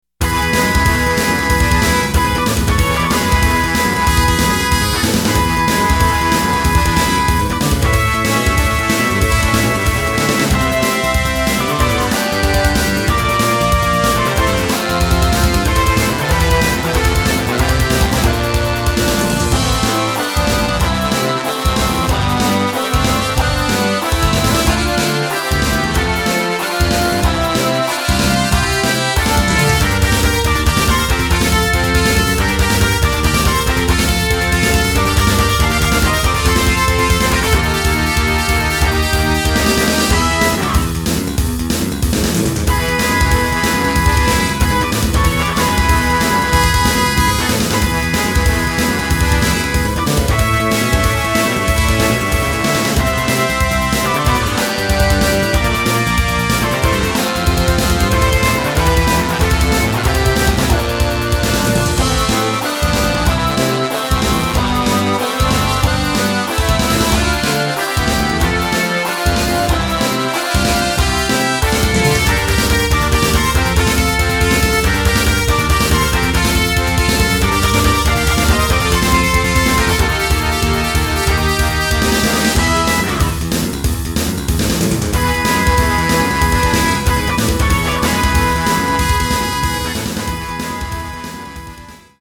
SC88Pro